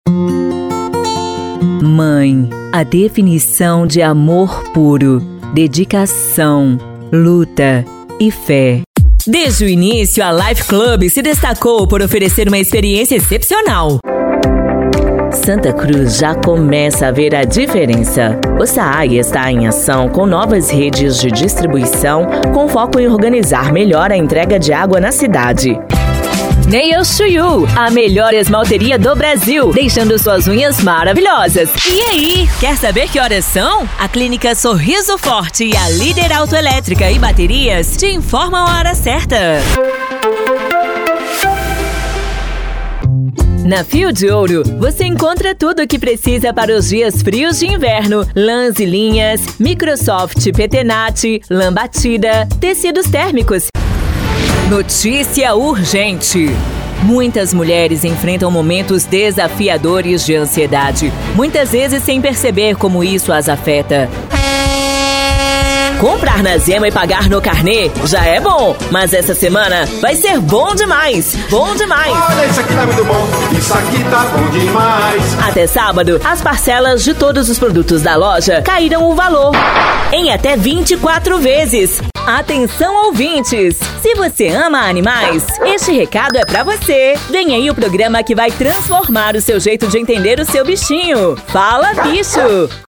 Minas Gerais
Spot Comercial
Vinhetas
Impacto
Animada